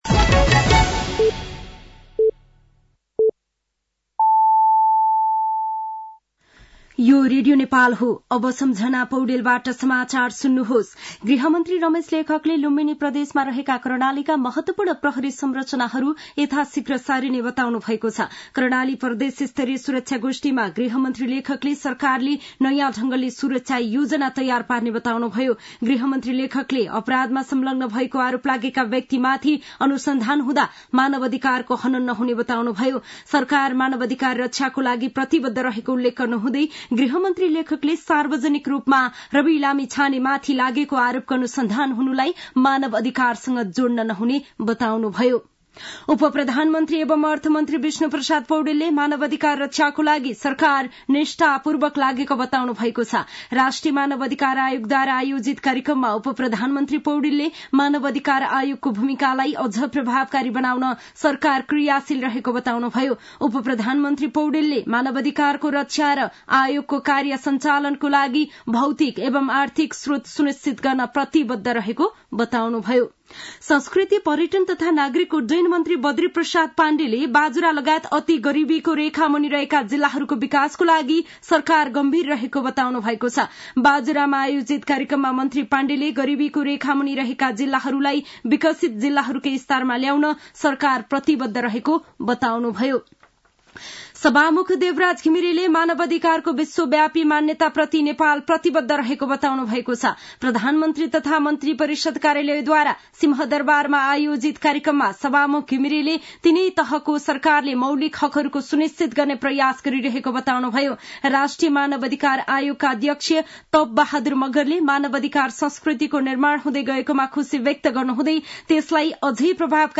साँझ ५ बजेको नेपाली समाचार : २६ मंसिर , २०८१
5-pm-nepali-news-8-25.mp3